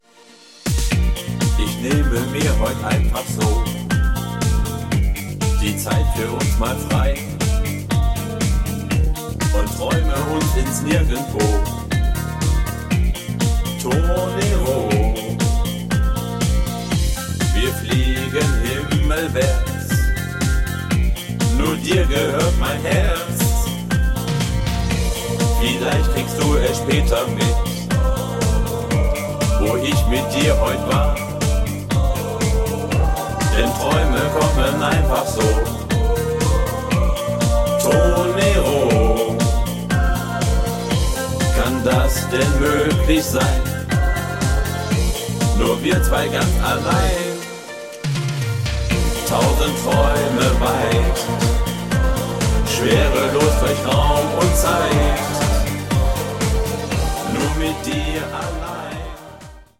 --- Party und Stimmung ---